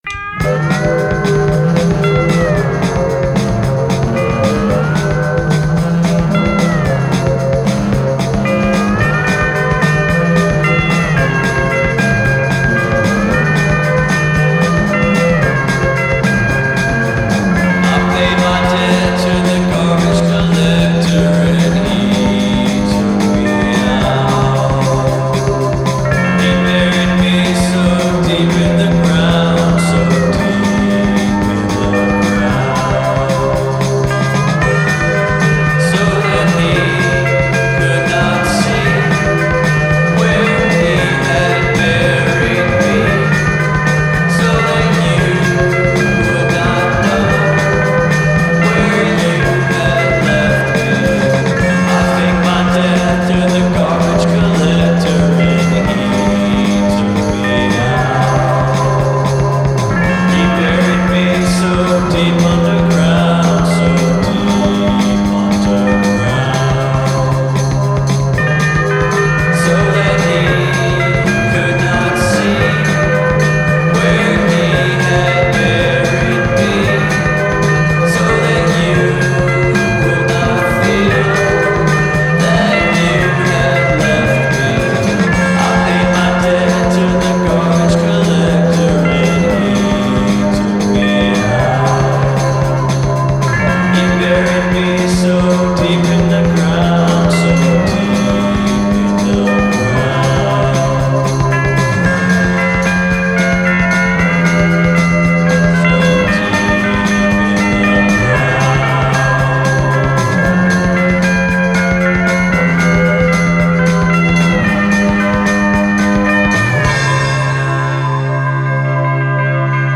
garage rock frastornato di psichedelia